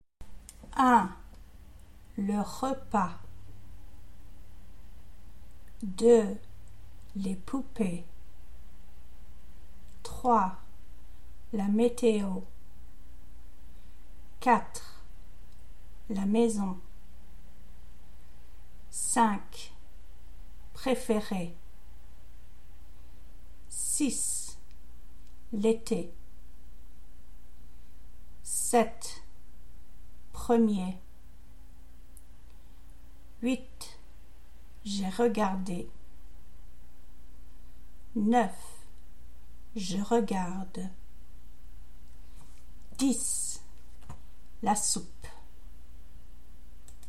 Pronunciation – /e/ vs /ə/